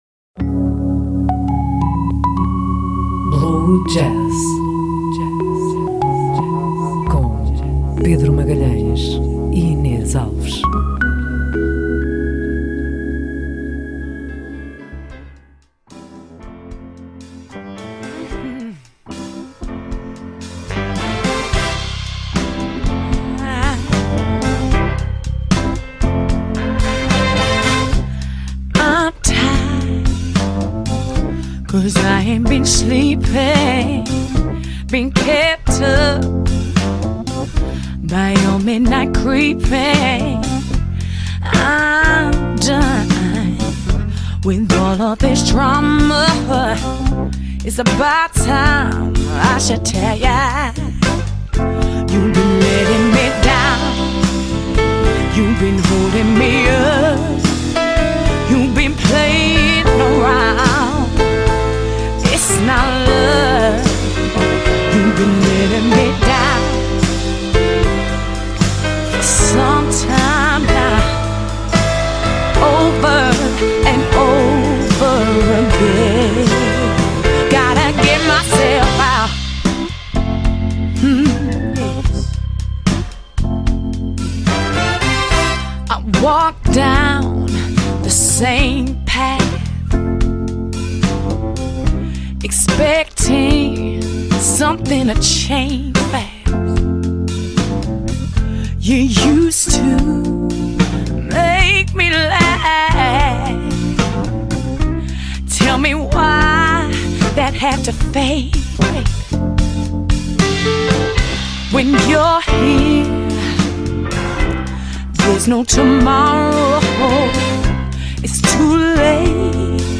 É com todo o gosto que o BlueJazz volta a gravar para homenagear ao ser mais belo da humanidade, vós mulheres! Como tal trazemos um programa excepcional repleto de música só cantada por mulheres de várias nacionalidades.